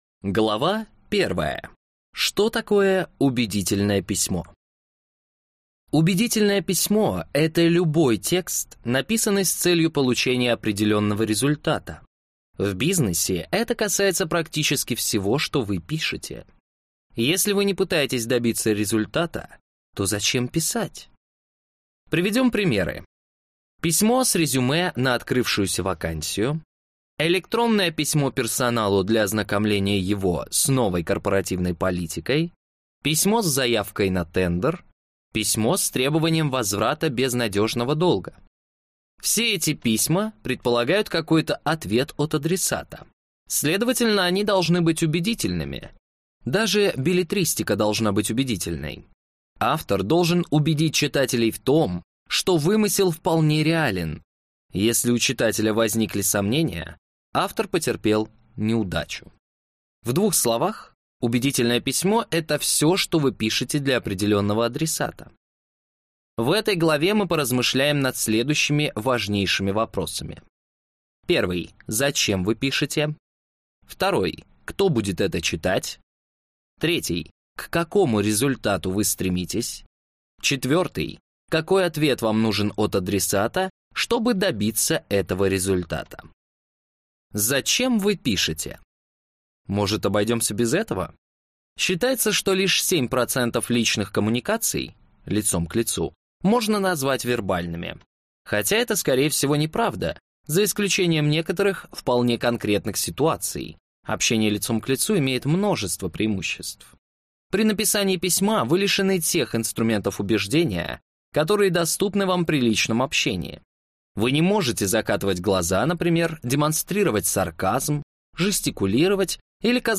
Аудиокнига Убедительное письмо. Как использовать силу слов | Библиотека аудиокниг